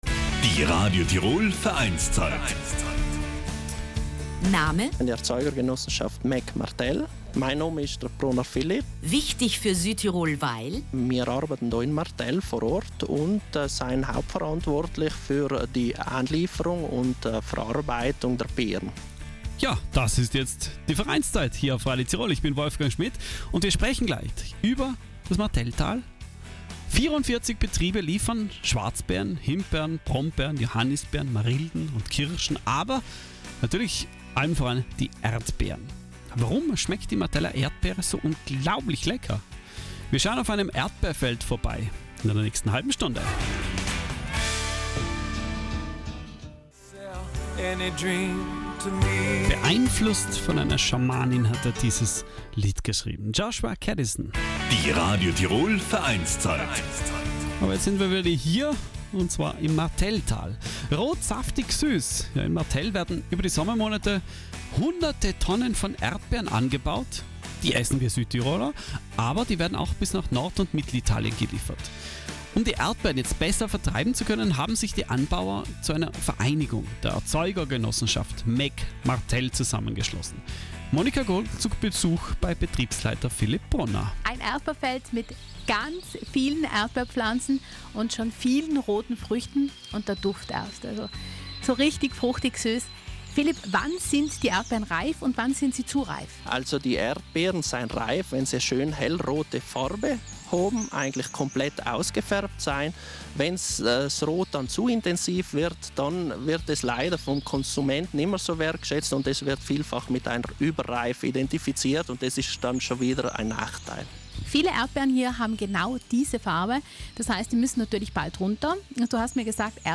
Um die Erdbeeren besser vertreiben zu können, haben sich die Anbauer zu einer Vereinigung zusammengeschlossen. Wir schauen auf einem riesigen Erdbeerfeld vorbei und fragen nach, wie Martell eigentlich zum Erdbeertal geworden ist.